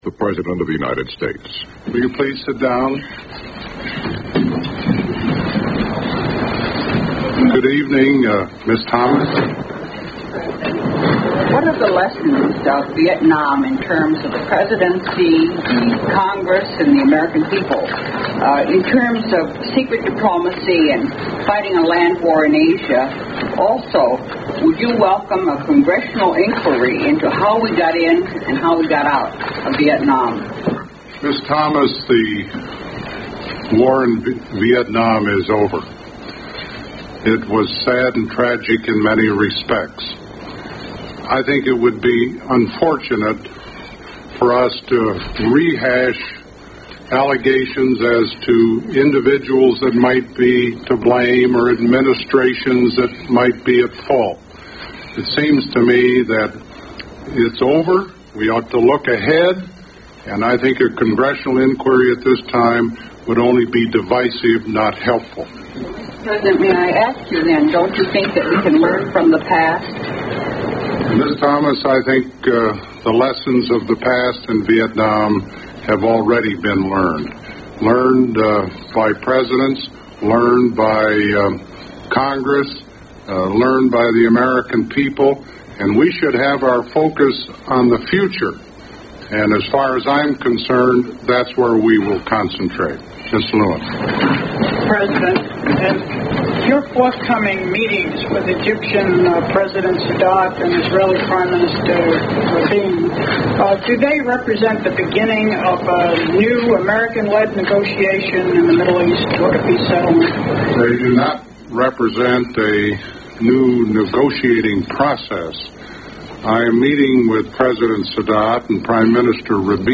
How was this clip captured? Broadcast on CBS-TV, May 6, 1975.